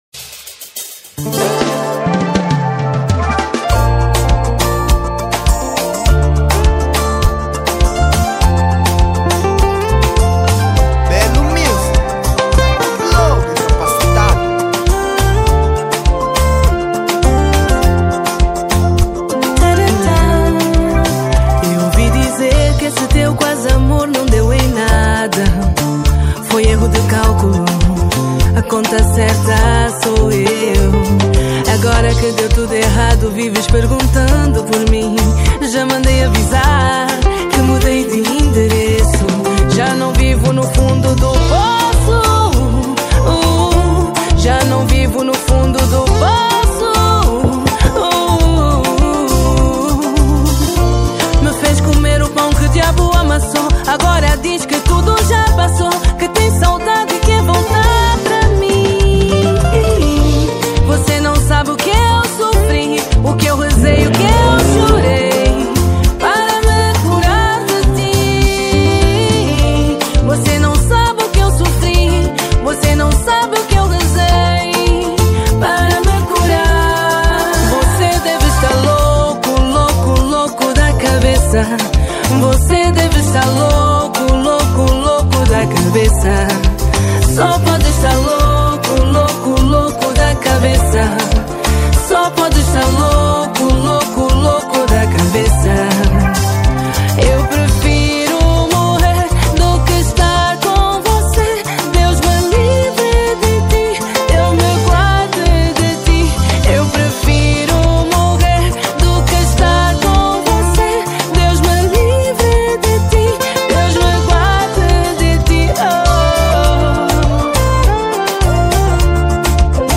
Género : Kizomba